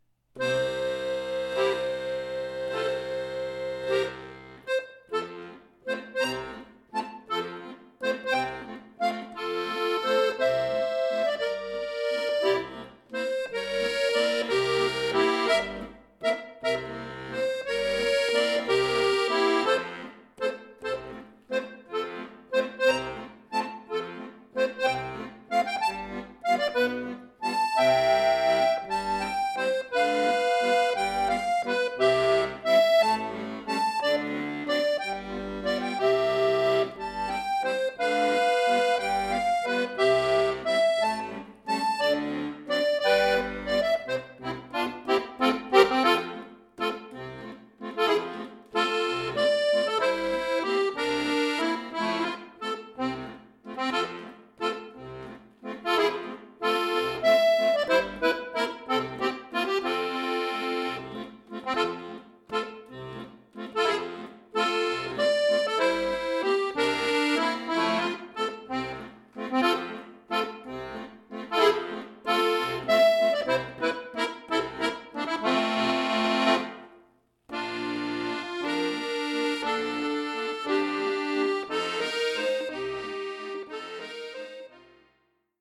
Walzer
in einem neuen und gekürzten Arrangement für Akkordeon solo
Klassisch, Walzer